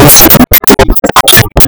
コンセンサス・ビルディングの実践の模様